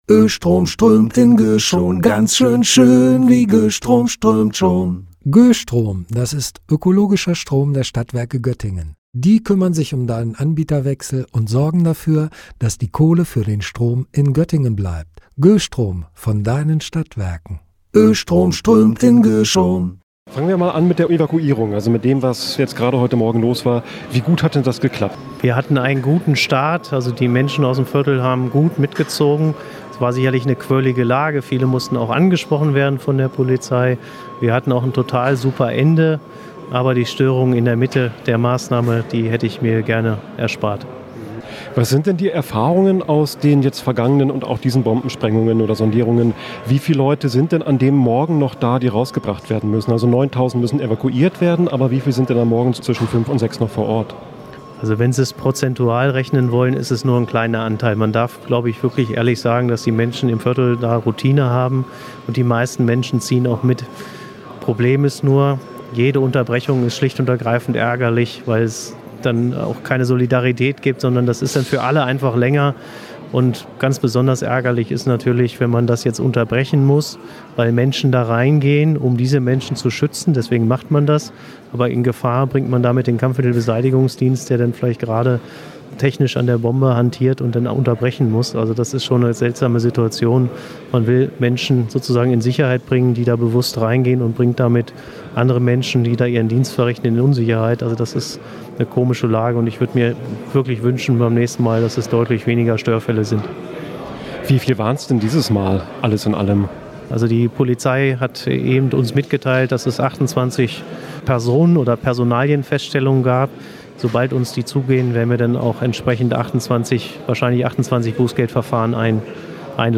Und weil alles so reibungslos lief, wurde die abschließende Pressekonferenz in der quirligen Einsatzzentrale der Berufsfeuerwehr Göttingen abgehalten.